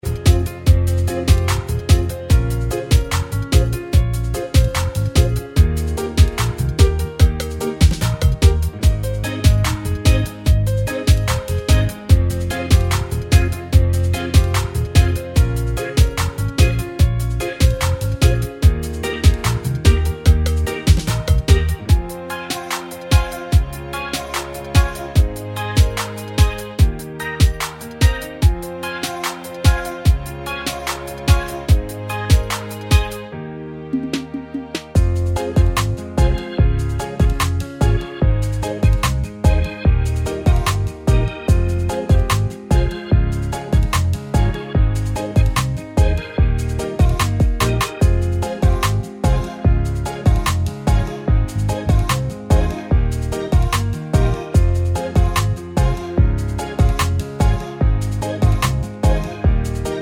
Reggae Version Reggae 3:54 Buy £1.50